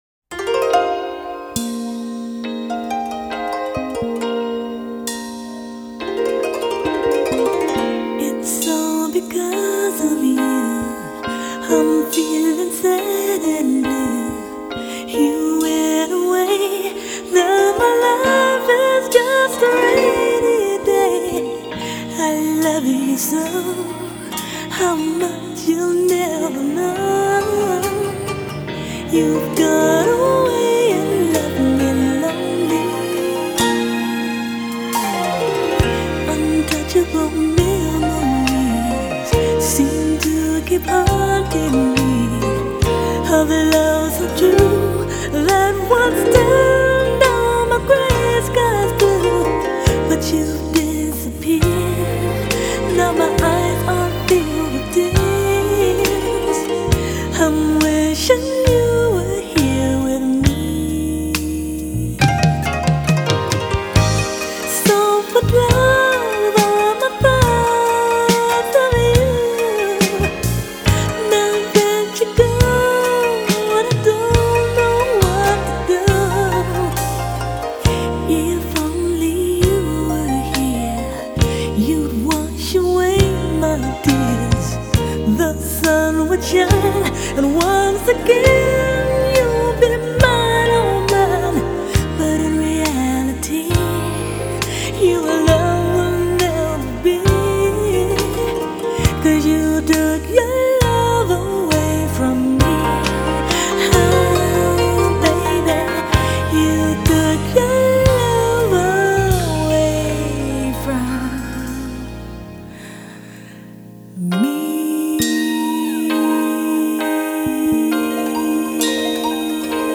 BPM40-75
Audio QualityMusic Cut